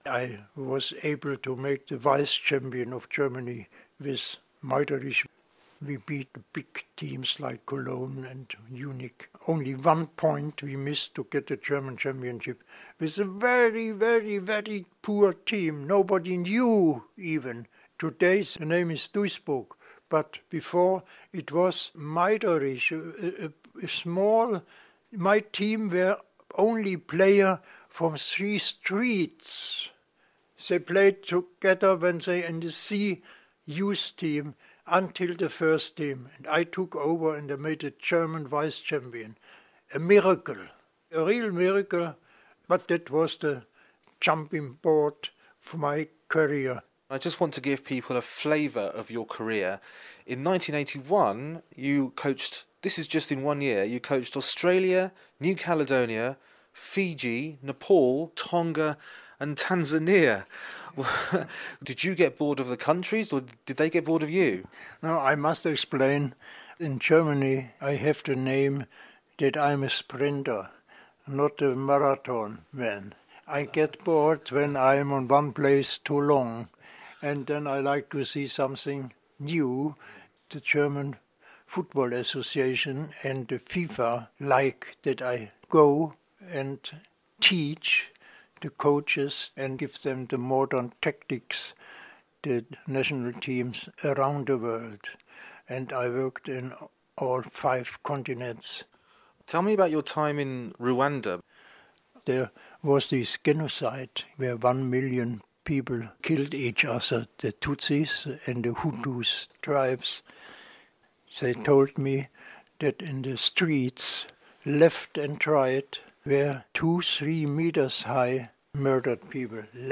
My interview with Rudi Gutendorf